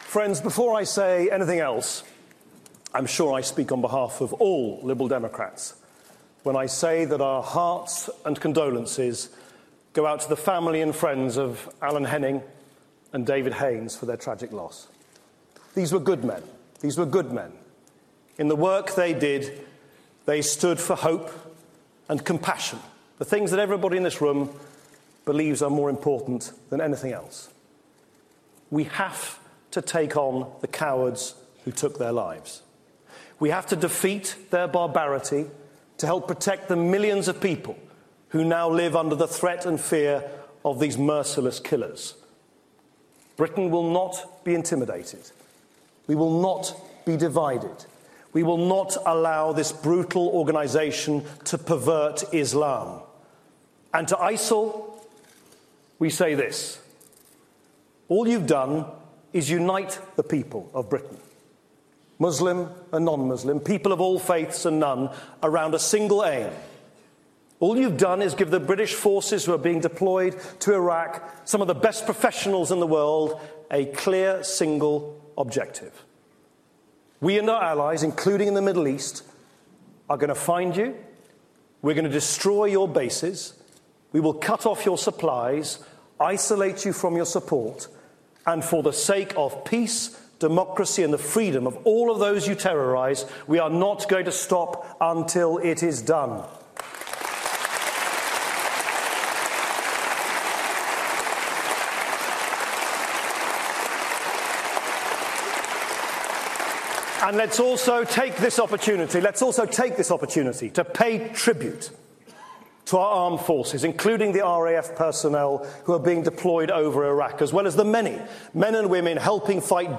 Nick Clegg's speech to the Lib Dem conference